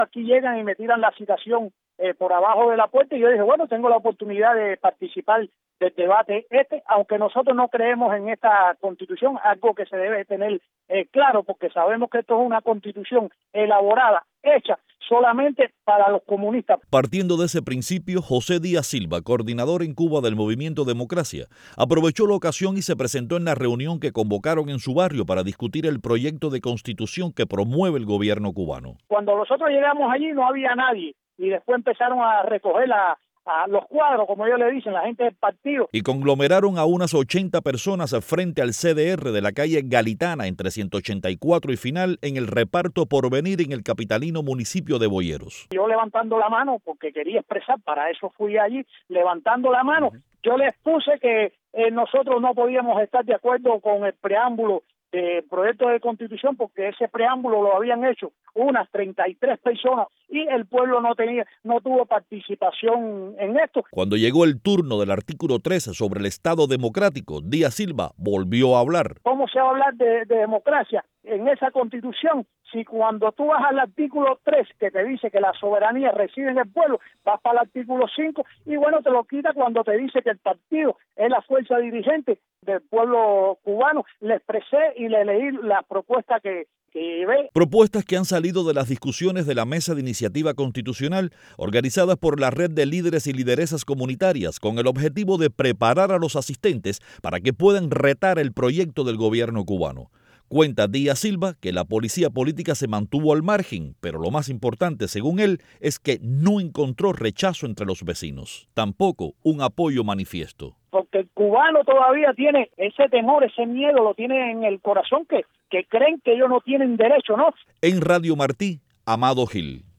Opositor critica reforma constitucional en una de las asambleas